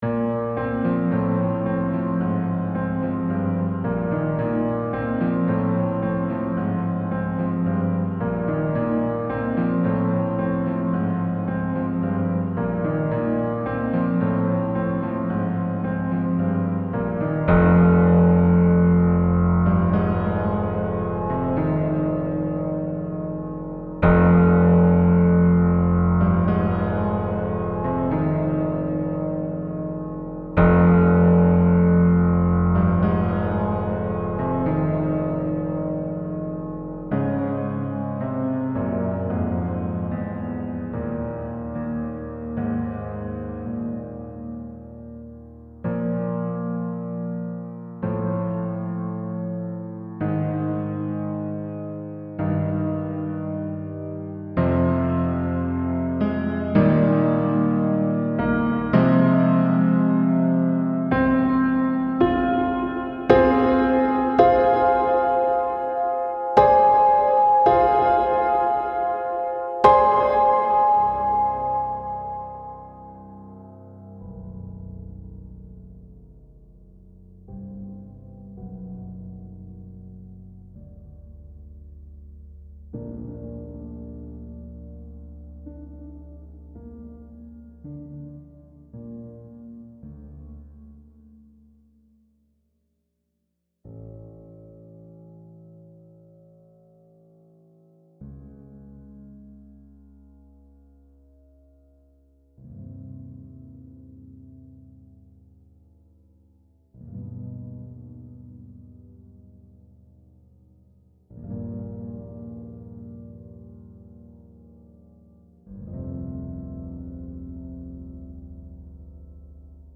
Description: This recording was sequenced in Steinberg Cubasis VST using IK Multimedia's Sampletank for the piano. The sequencing was tweaked to contain a few of the subtle human elements that are necessary to get an appreciation of the piece.